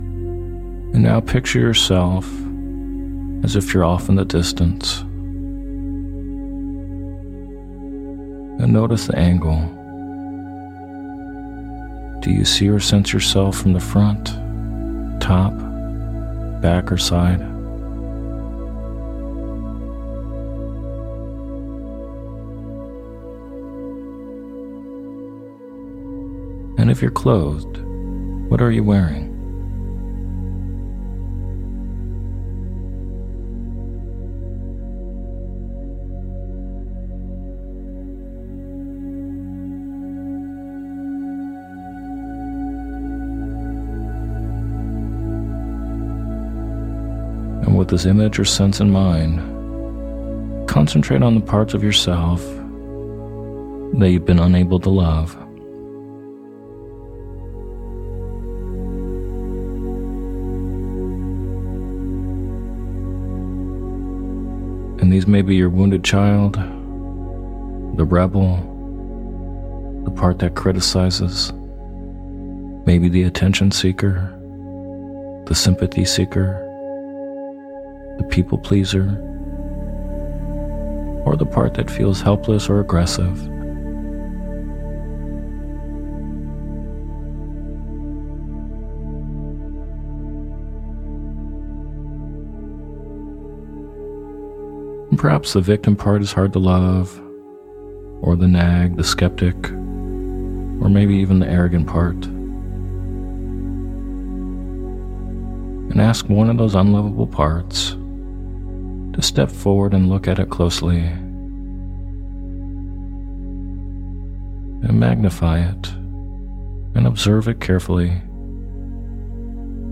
Sleep Hypnosis For Loving Parts Of The Self